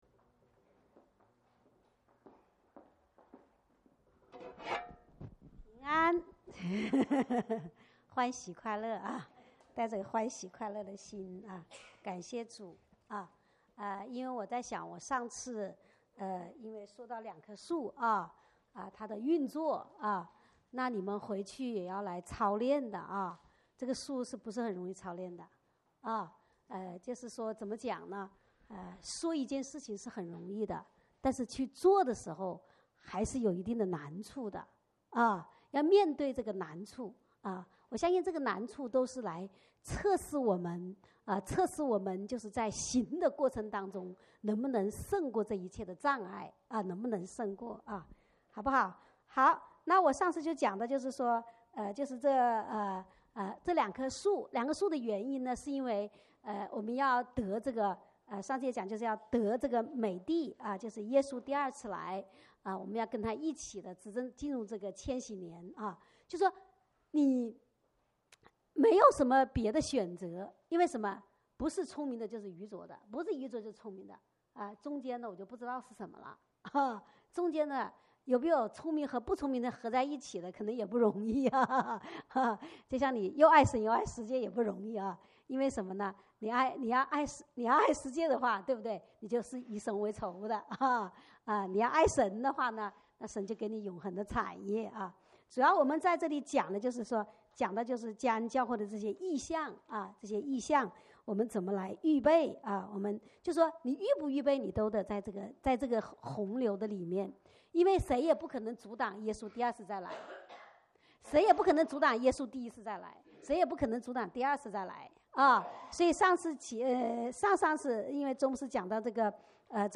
主日恩膏聚会录音